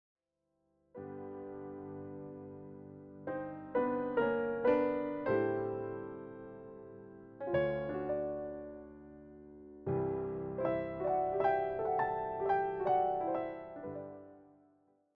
The performance favors clean voicing and balanced dynamics